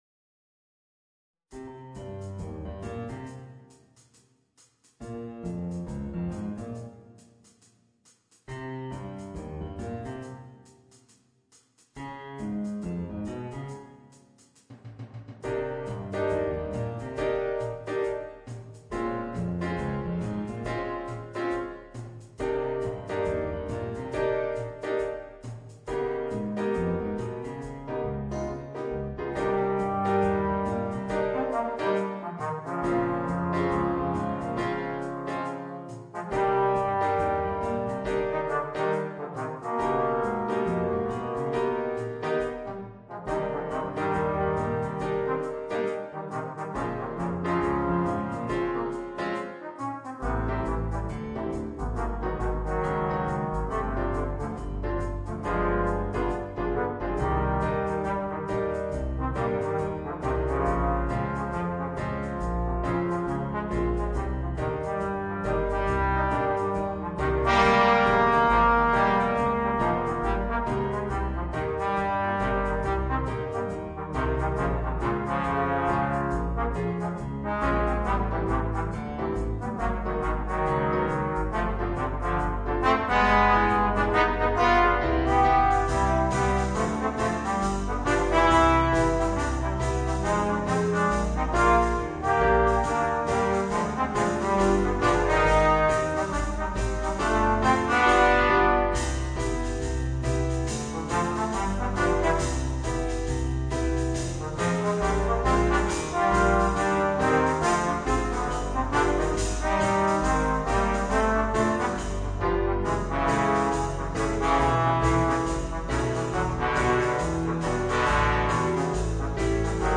2 Trombones